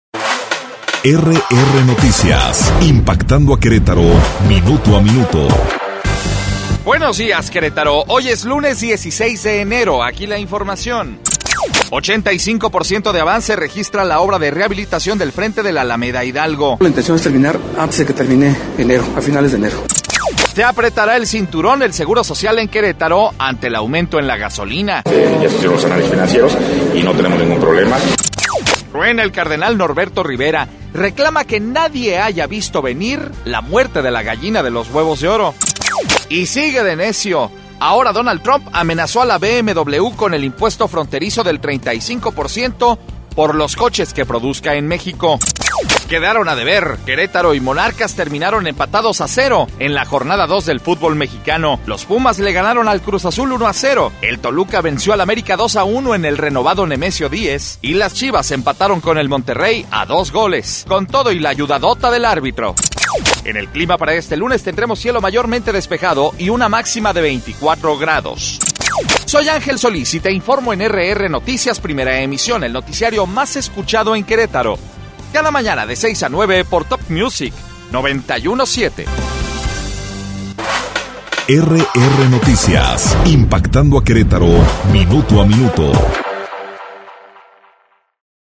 Resumen Informativo 16 de enero - RR Noticias